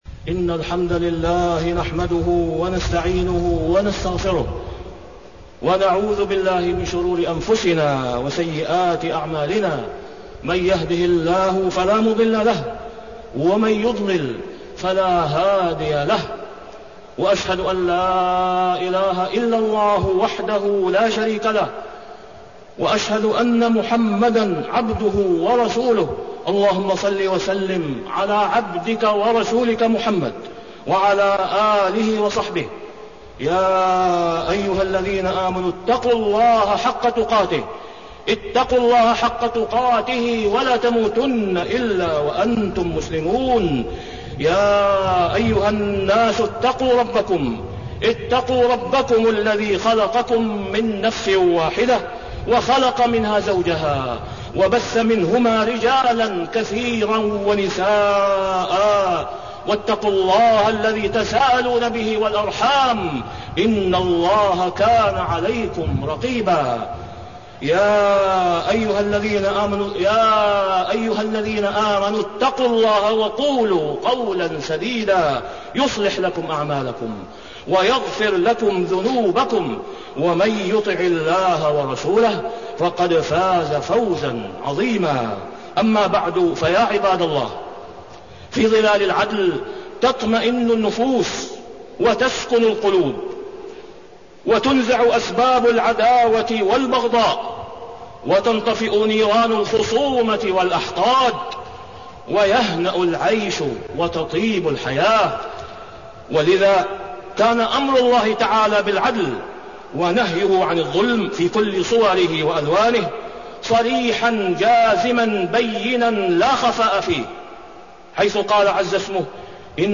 تاريخ النشر ٢١ صفر ١٤٣١ هـ المكان: المسجد الحرام الشيخ: فضيلة الشيخ د. أسامة بن عبدالله خياط فضيلة الشيخ د. أسامة بن عبدالله خياط مراعاة الأجراء وإعطاءهم حقوقهم